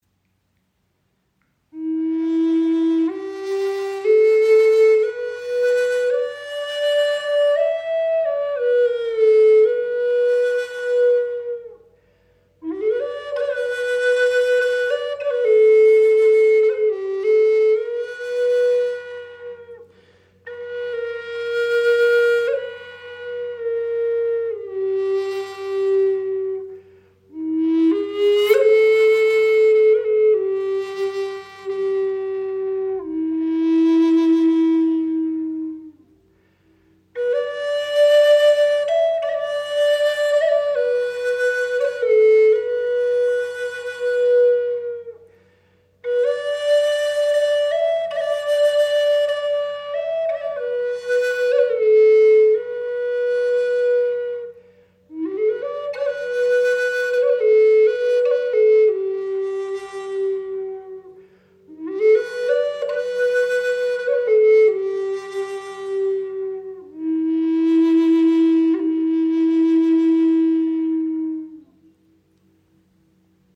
Gebetsflöte in tiefem E - 432 Hz
Sie schenkt Dir ein wundervolles Fibrato, kann als Soloinstrument gespielt werden oder als weiche Untermahlung Deiner Musik.